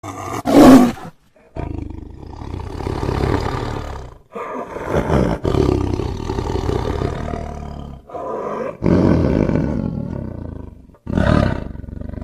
Klingelton Tiger Sound
Kategorien Tierstimmen